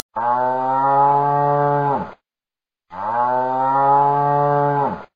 Tono de llamada Mugido de una vaca